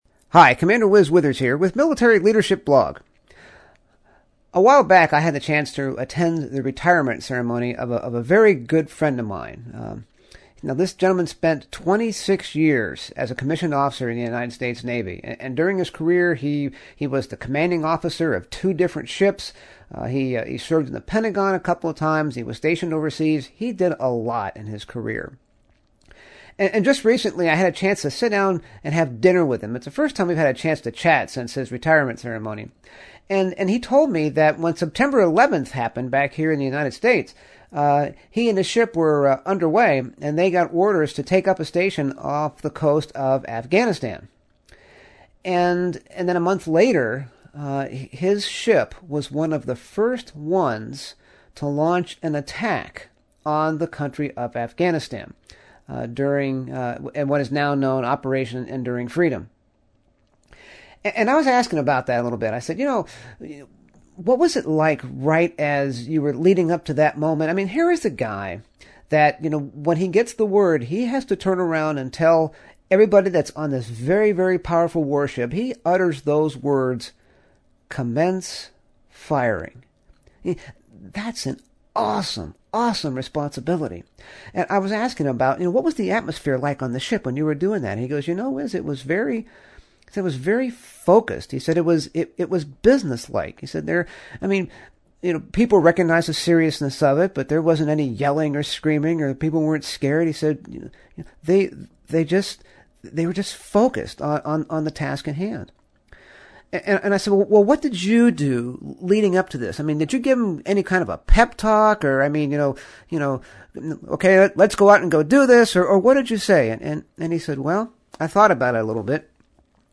I recently had the opportunity to interview a modern “master and commander”.